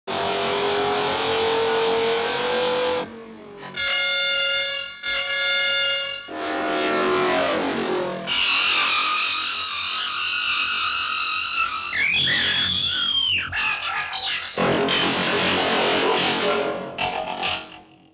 Alien Car Taxi Crashed
Cartoon Crashed Funny High-pitch sound effect free sound royalty free Funny